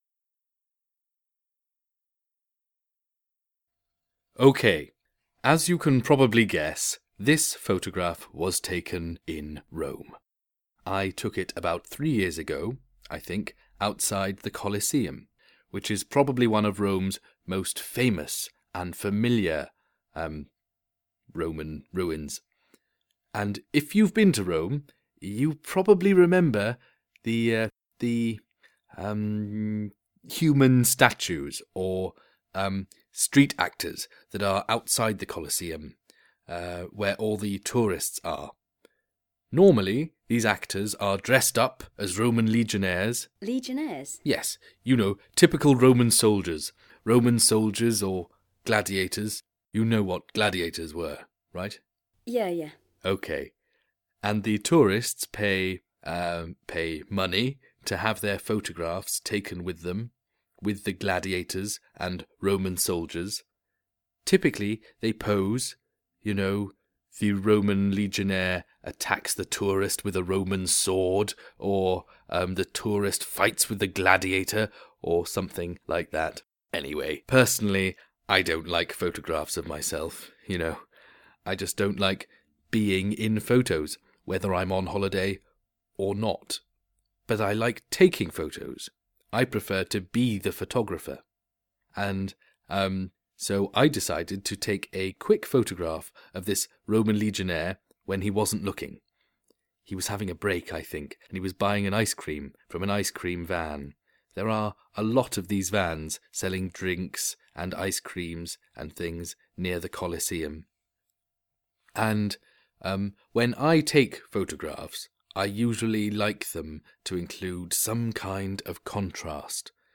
This lesson focuses on a holiday image. Students do a number of activities to help them understand a recording of the photographer talking about the image.
Identify specific and detailed information in a recorded interview